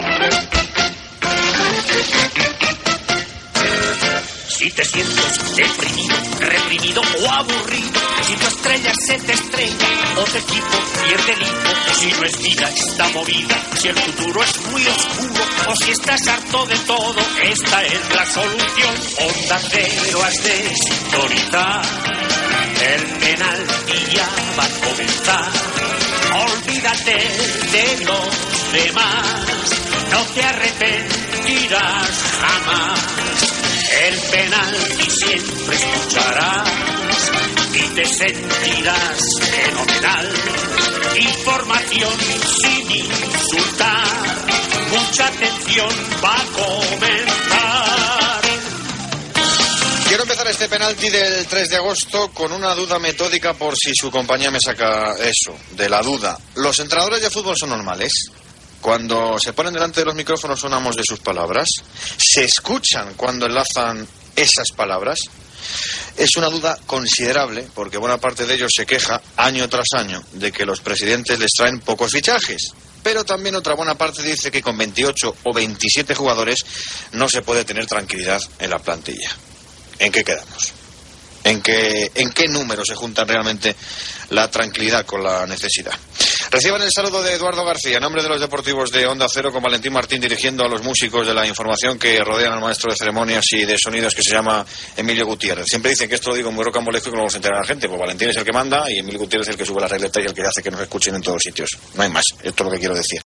Sintonia cantada del programa, comentari sobre els entrendors de futbol, equip
Esportiu